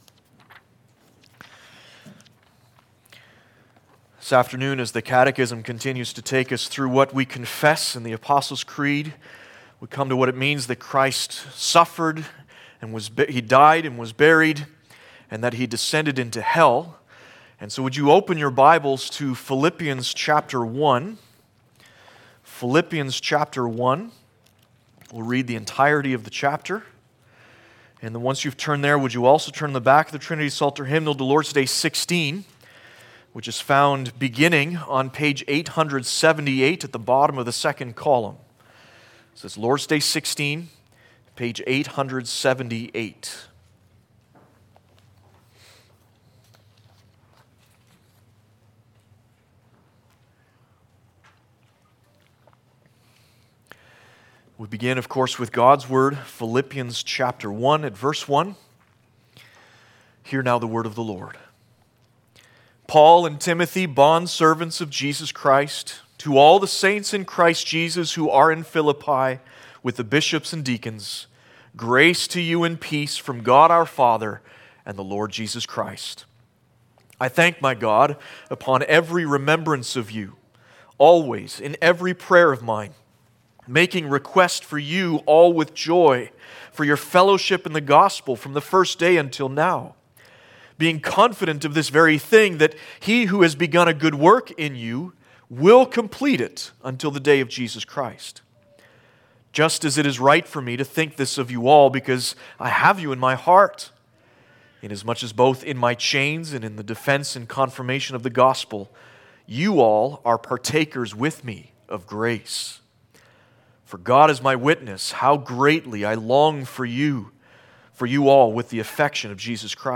Service Type: Sunday Afternoon
4.-Service-of-Gods-Word.mp3